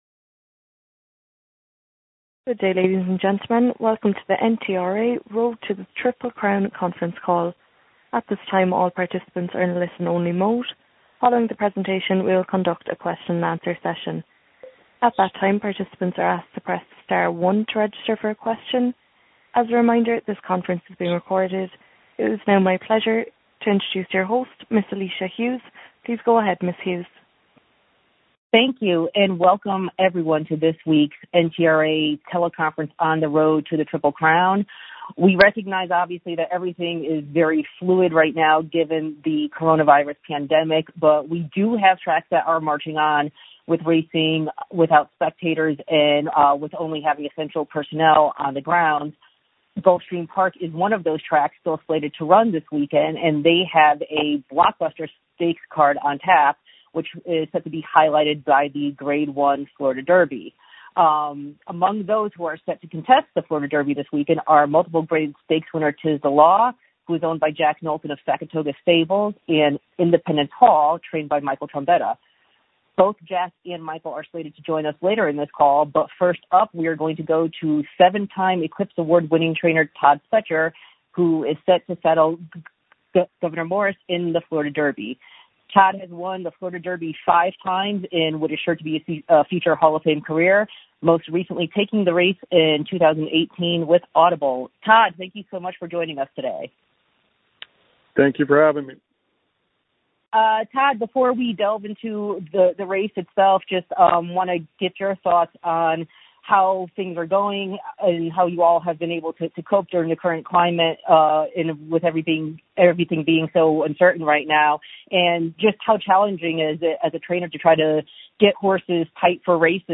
National Media Teleconference